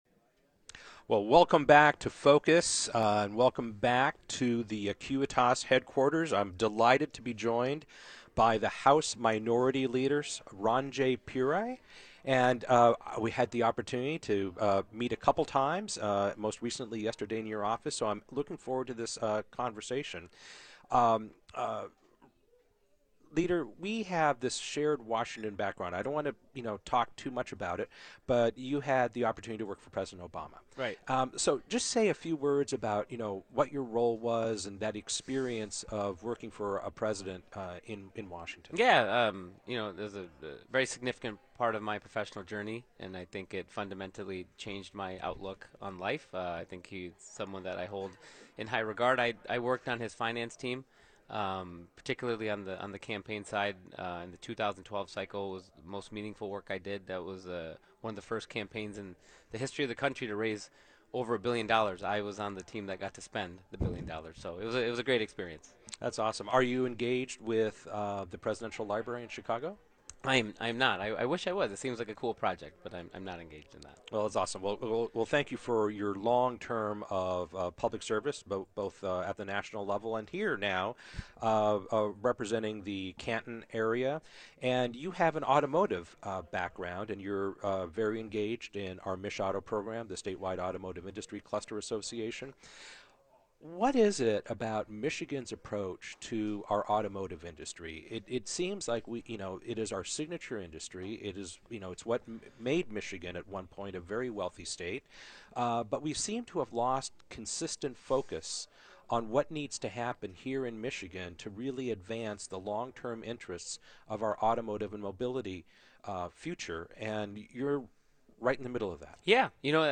interviewing several prominent leaders in the Detroit Region as they awaited Governor Gretchen Whitmer’s final State of the State address.